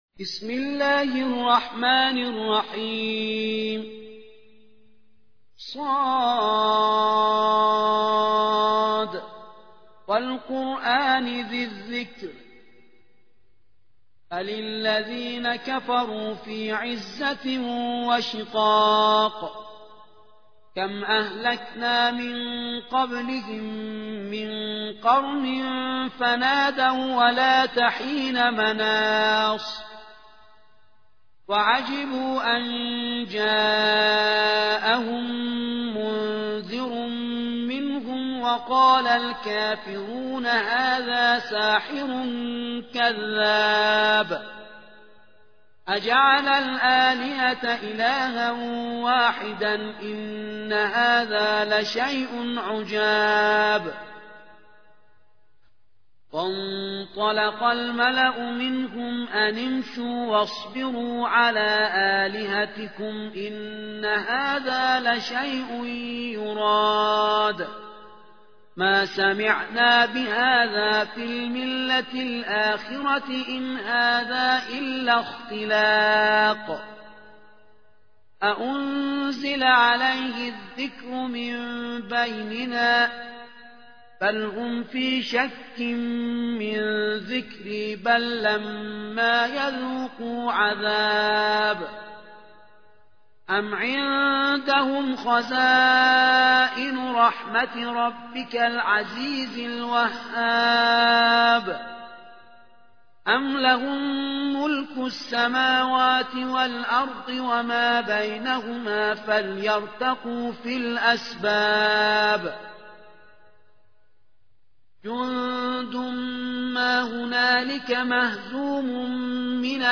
38. سورة ص / القارئ